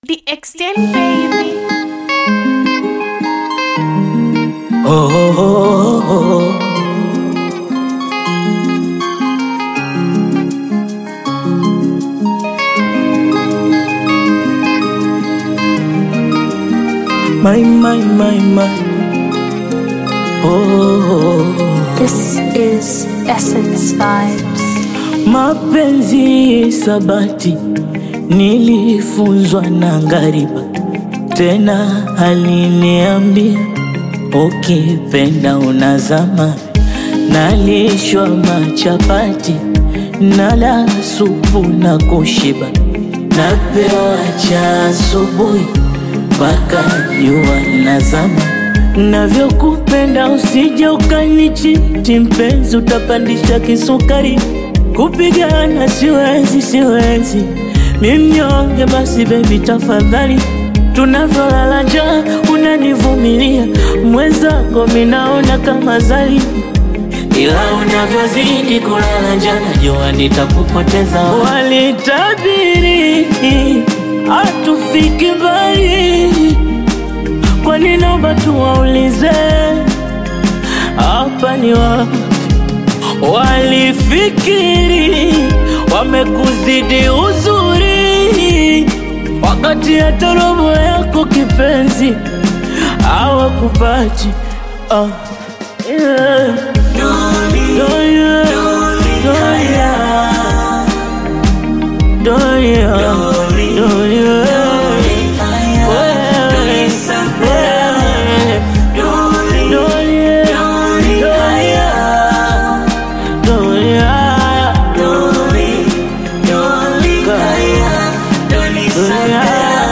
Bongo Flava/Afro-Pop collaboration
expressive vocals